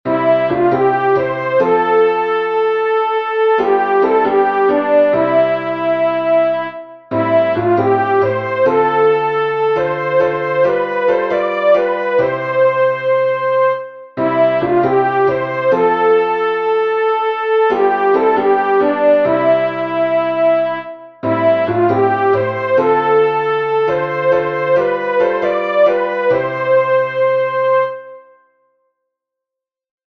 Soprano
open_wide_the_doors-sop.mp3